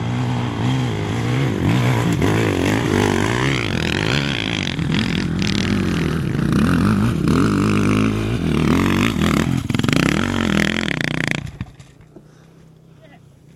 越野车 " 摩托车 越野车 越野车 接近爬坡 斗争，停止2
描述：摩托车越野车越野摩托车越野爬山，停止